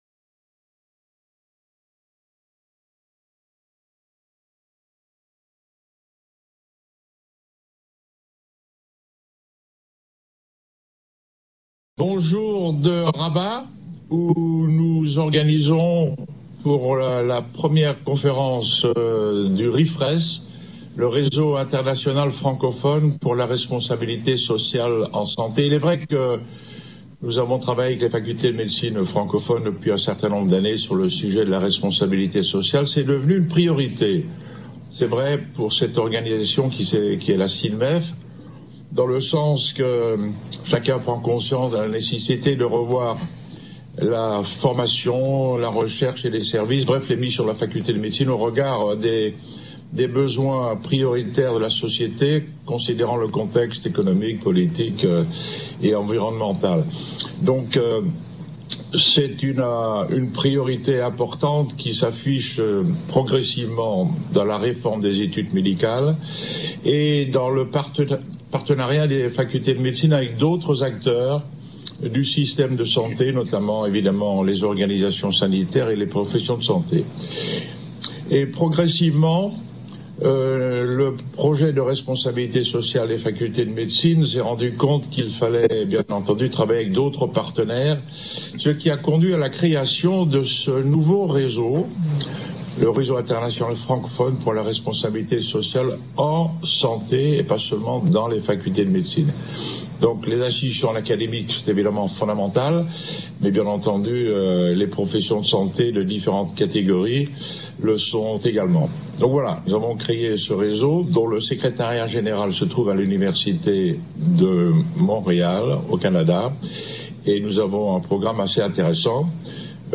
Interview en visio conférence depuis Rabat